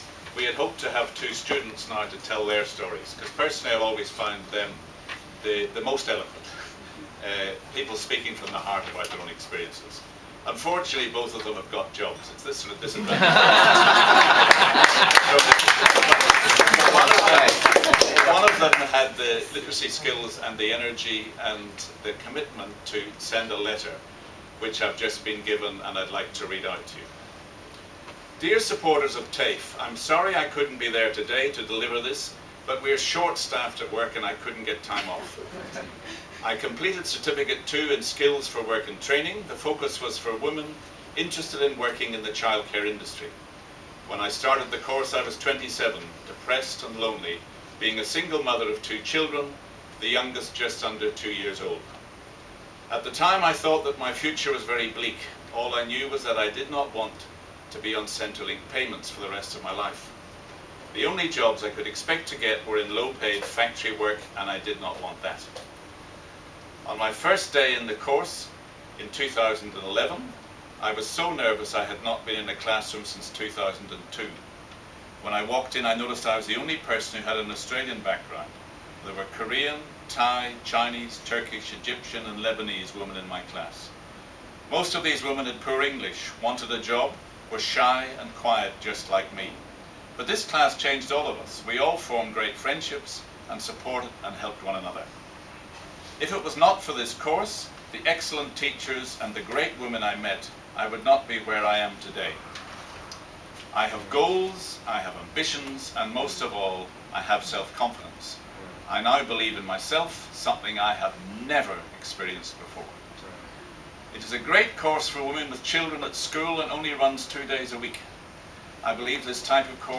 Student letter audioDownload full text
On February 22 2013 the TAFE Community Alliance was formally launched in the heart of western Sydney at Western Sydney Community Forum, Parramatta.